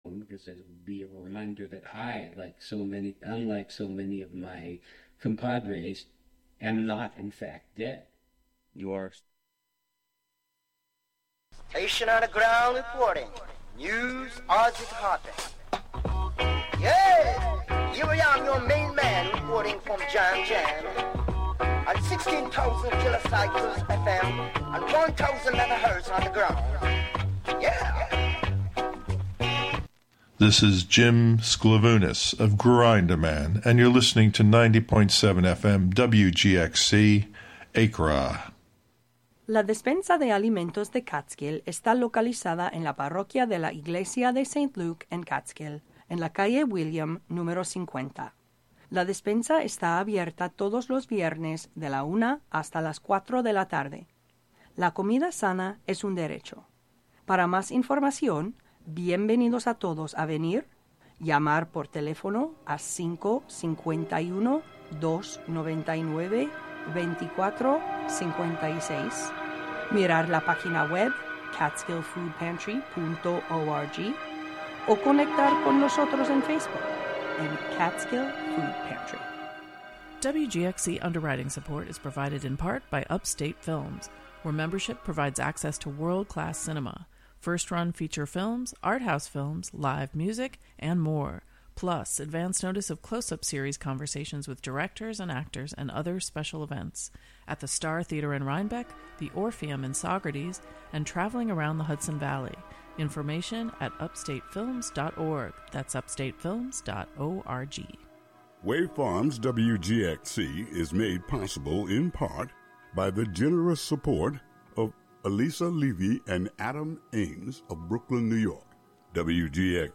On this monthly program, “La Ville Inhumaine” (The Inhuman City), you will hear music, found sounds, words, intentional noise, field recordings, altogether, all at once.